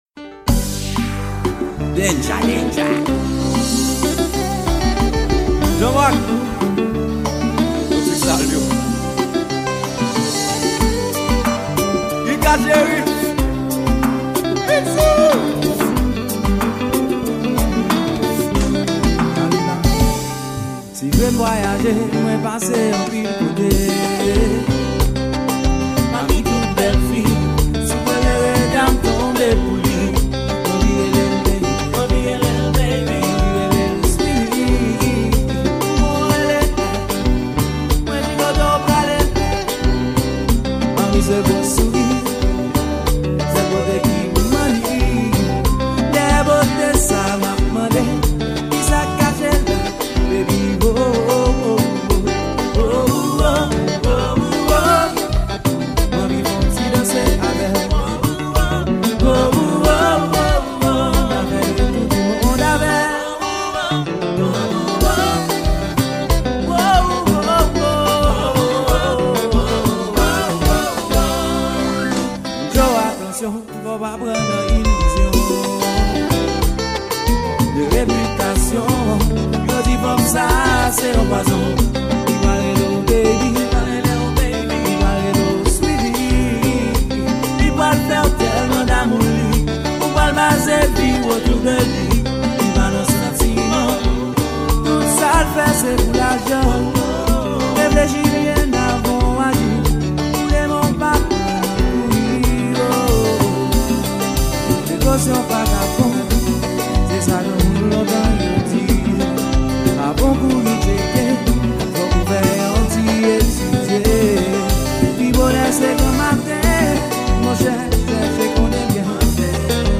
Genre: KONPA LIVE.